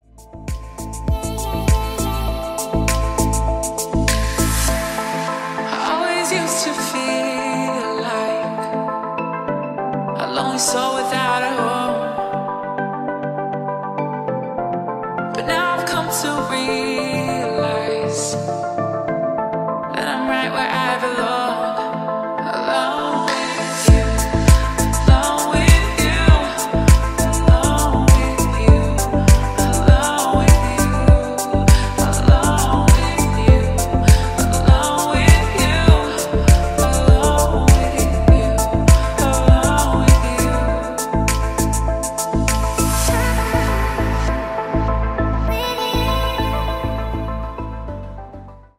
• Качество: 128, Stereo
deep house
атмосферные
Electronic
спокойные
релакс
чувственные
медленные
красивый женский голос
Chill